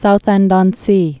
(southĕnd-ŏn-sē, -ôn-)